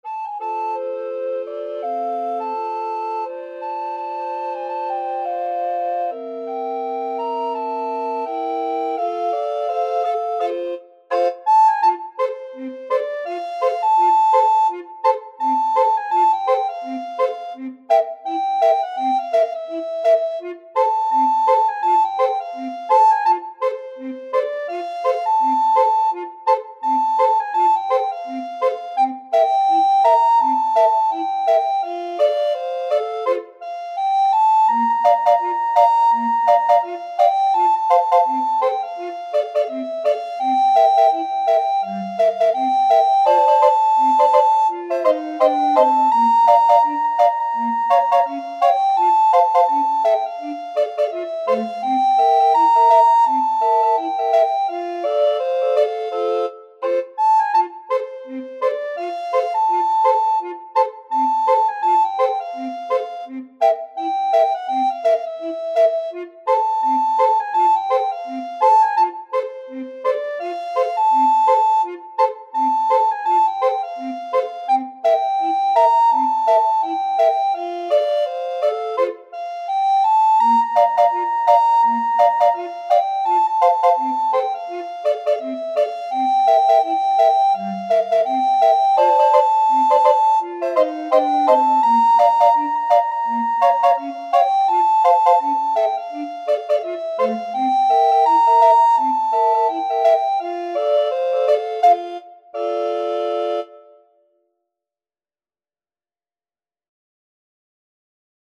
Soprano RecorderAlto RecorderTenor RecorderBass Recorder
Calmly =c.84
2/2 (View more 2/2 Music)
Recorder Quartet  (View more Easy Recorder Quartet Music)